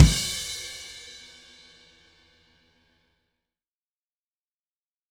Trem Trance Drums Ending.wav